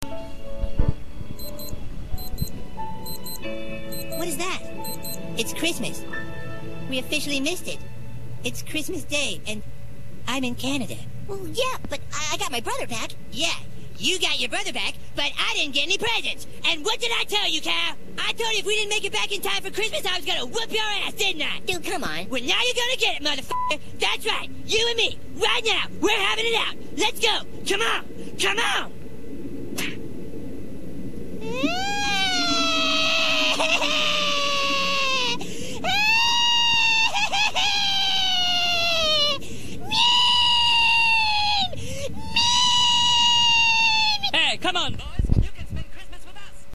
Cartman gets hit, and cries
Cartmans wants to fight, but then he gets punched and cries, he cries funny.